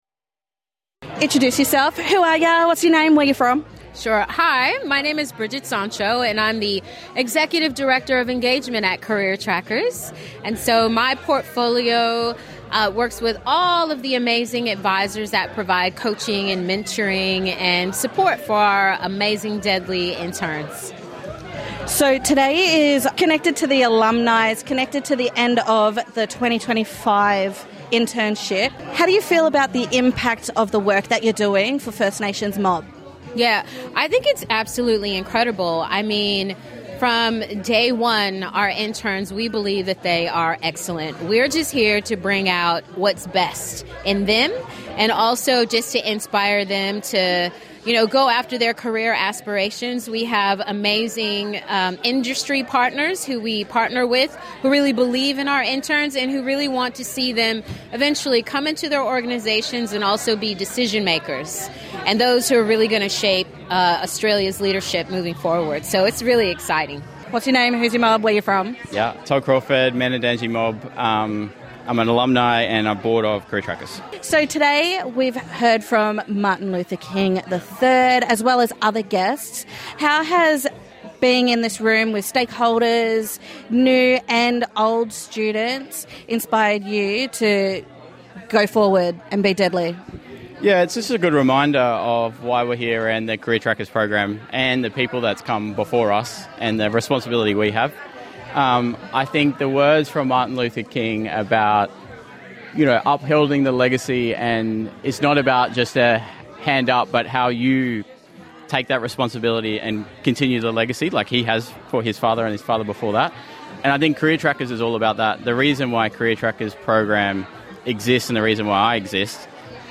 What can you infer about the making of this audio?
NITV Radio catch up with some of the attendees of the Welcoming ceremony at the Sydney Opera House and day one of the multi-day conference.